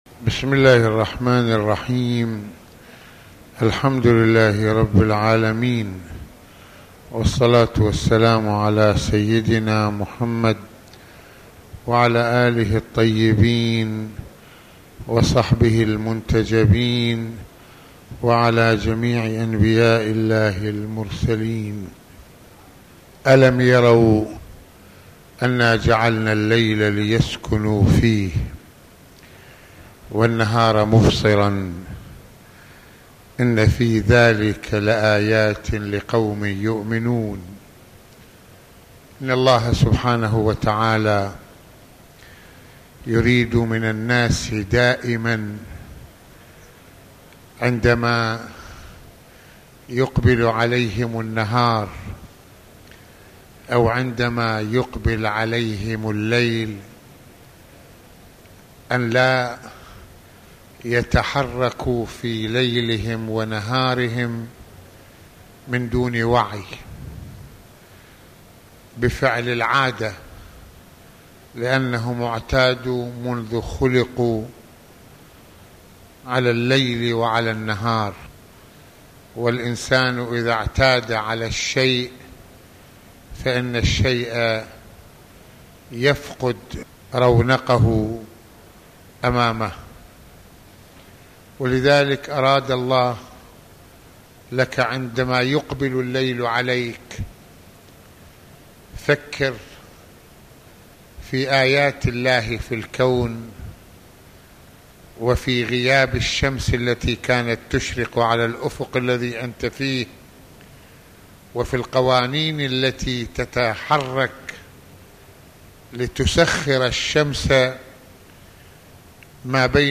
- المناسبة : موعظة ليلة الجمعة المكان : مسجد الإمامين الحسنين (ع) المدة : 29د | 46ث المواضيع : آية الليل والنهار - البعث من الموت - أجر الحسنة يوم القيامة - النبي (ص) وتبليغ الرسالة - إطلاع الله على الانسان.